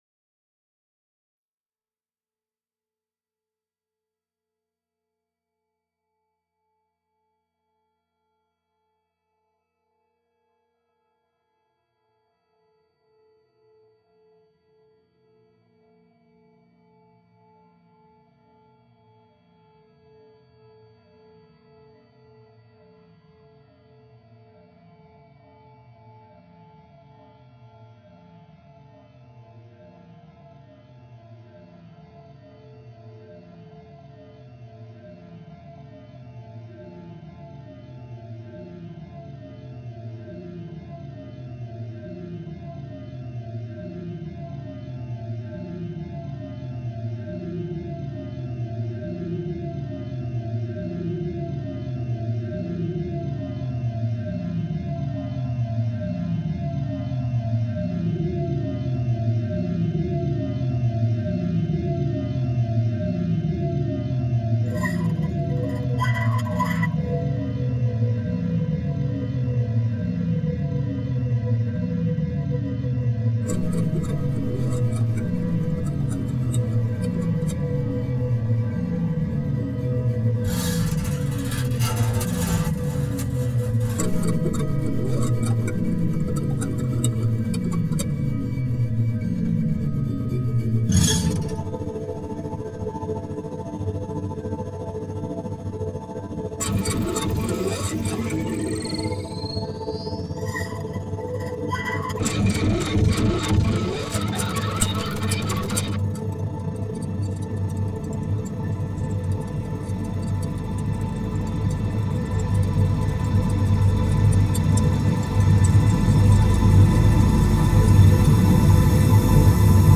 acousmatic composition